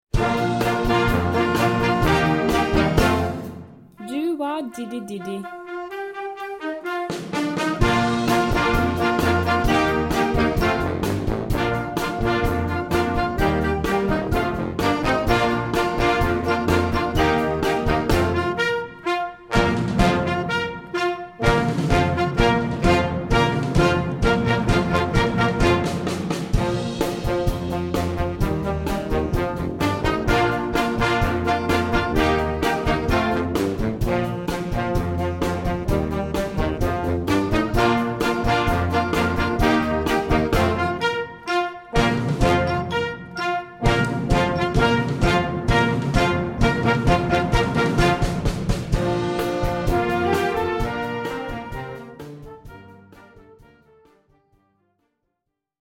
Gattung: Jugendmusik
Besetzung: Blasorchester